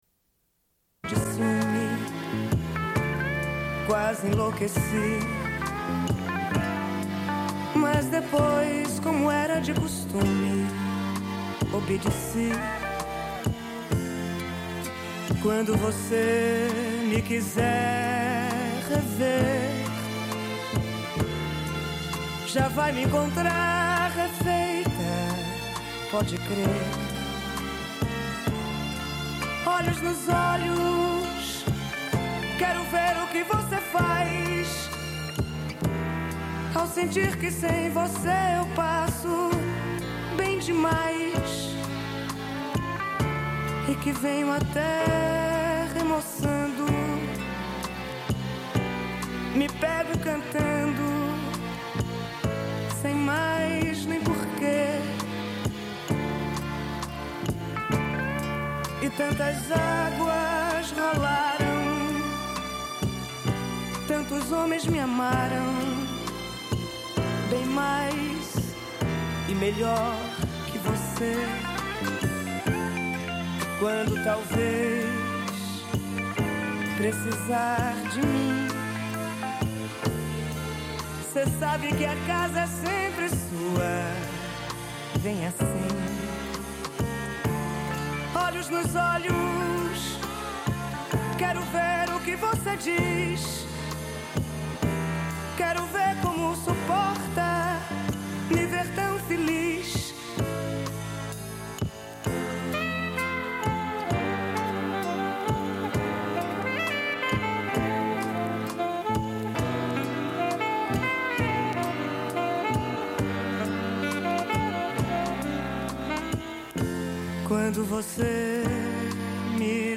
Une cassette audio, face B
Radio